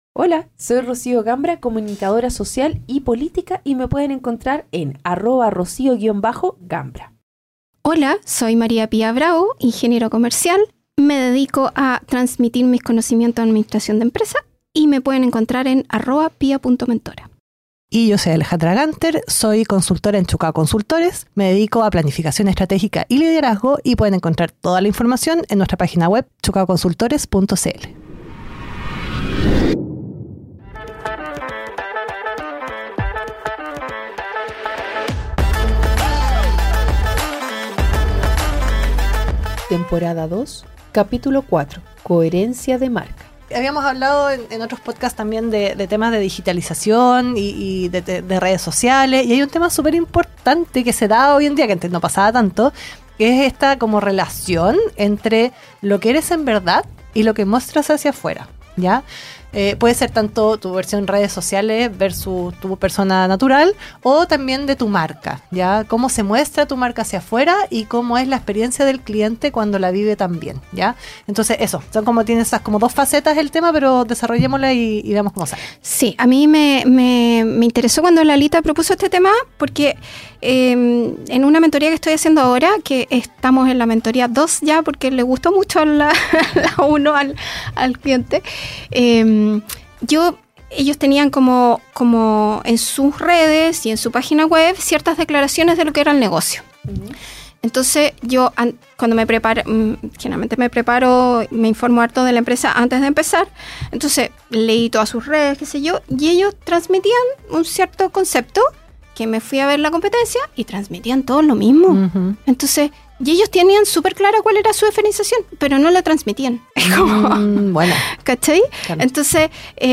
Somos tres amigas